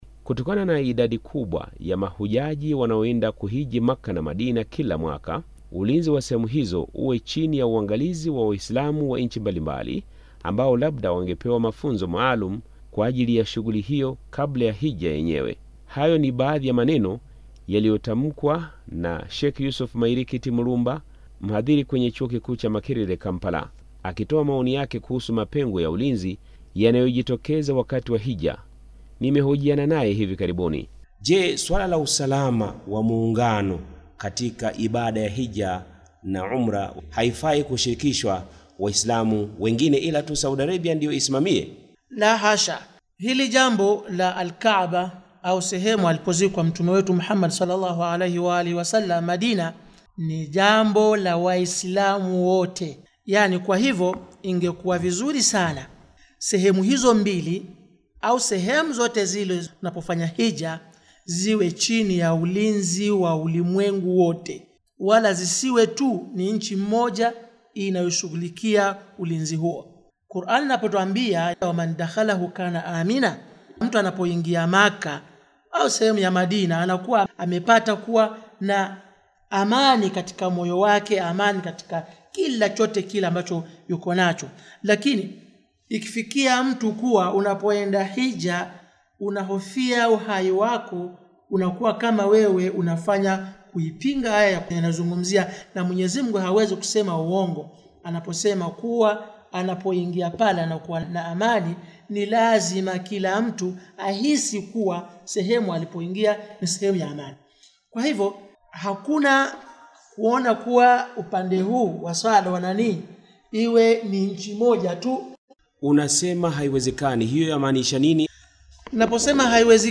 Waislamu nchini Uganda wamehimiza kusimamiwa kimataifa amali ya Hija hasa kutokana na vifo vingi vilivyotokea katika msimu wa mwaka jana wa Hija. Mwandishi wa Radio Tehran ametuandalia ripoti ifuatayo kutoka Kampala.